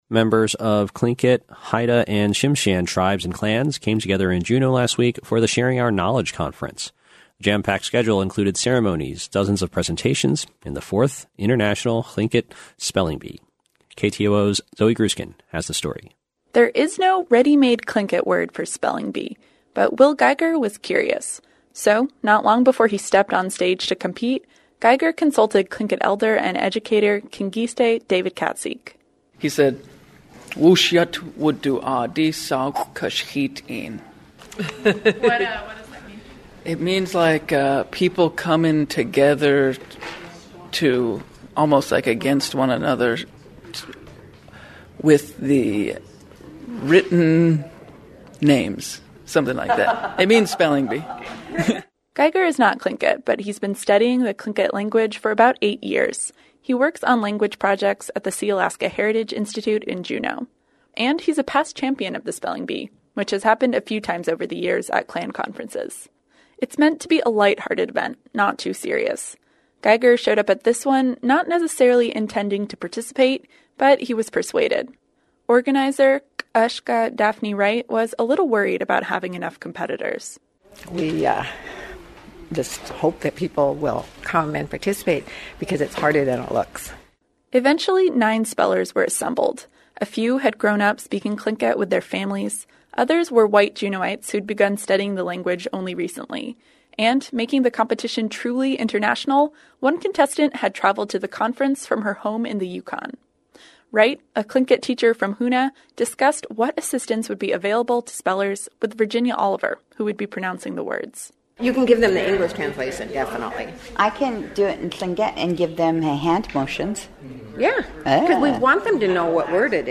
The jam-packed schedule included ceremonies, dozens of presentations — and the Fourth International Lingít Spelling Bee.